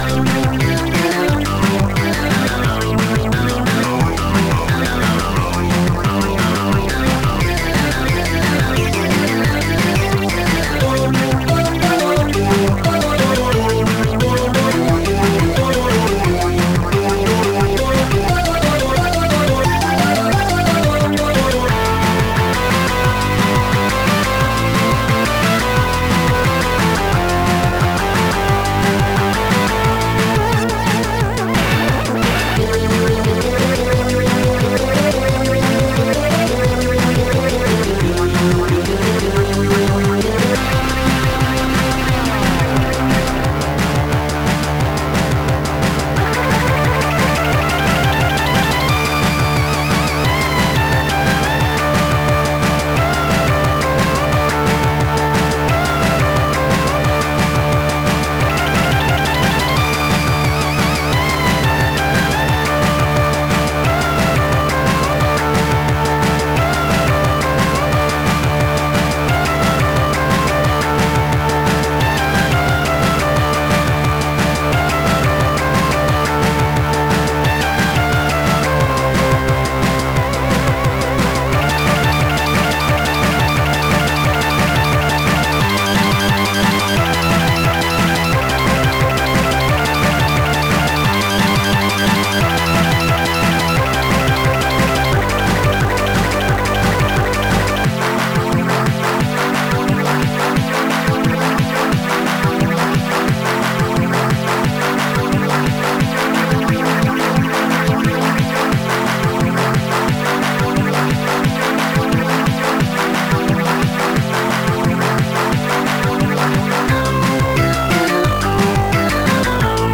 Digital gametune 5
This game features digital title music
Music written with Quartet